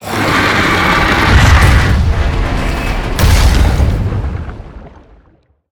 File:Sfx creature chelicerate seatruckattack exit 01.ogg - Subnautica Wiki
Sfx_creature_chelicerate_seatruckattack_exit_01.ogg